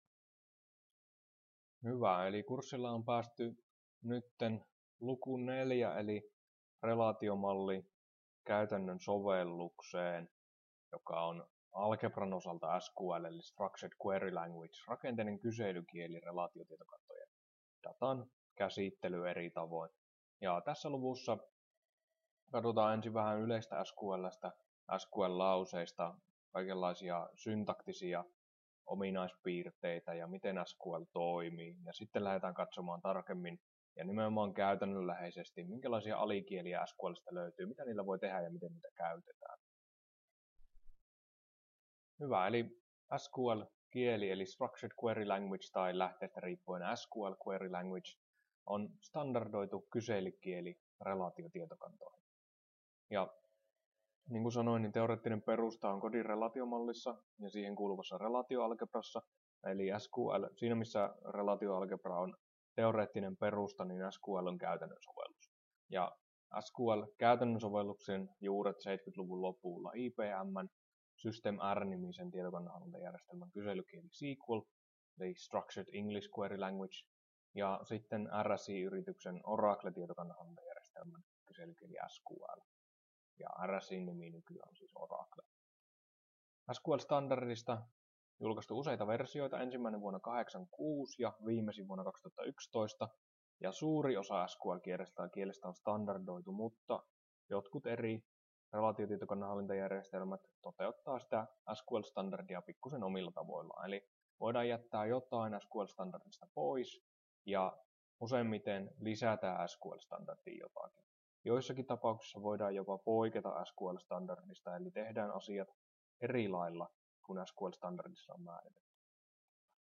Luento 5 osa 2 — Moniviestin